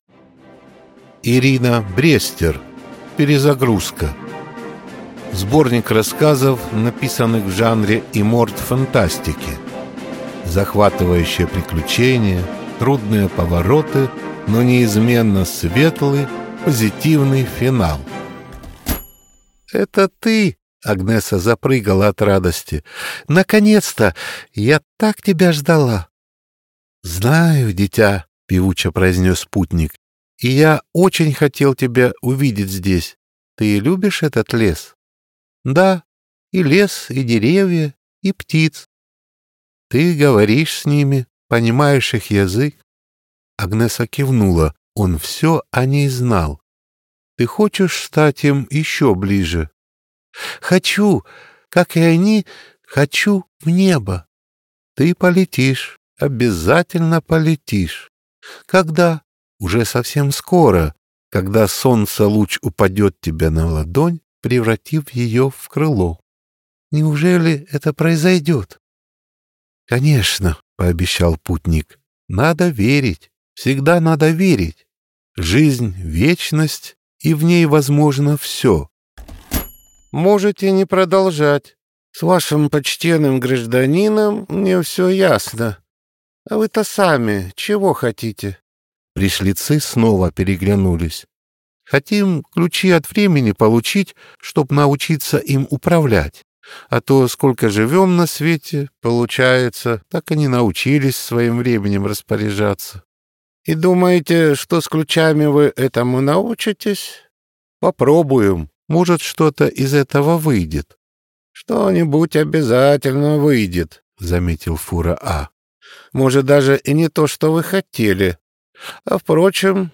Аудиокнига Перезагрузка. Сборник фантастических рассказов | Библиотека аудиокниг